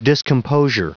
Prononciation du mot discomposure en anglais (fichier audio)
Prononciation du mot : discomposure